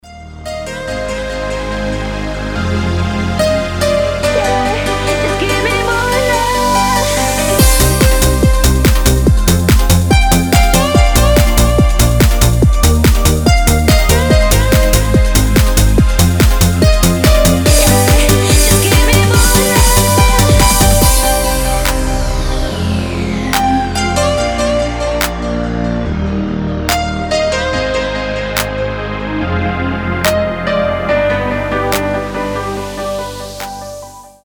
мелодичные
euro disco
Под диско музыка